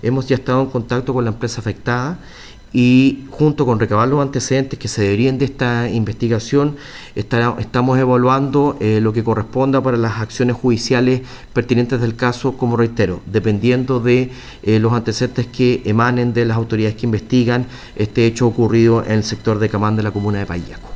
El delegado presidencial subrogante de Los Ríos, Alejandro Reyes, lamentó lo ocurrido y señaló que se encuentran a la espera de todos los antecedentes para la posible presentación de querellas.
delegado-ataque-paillaco.mp3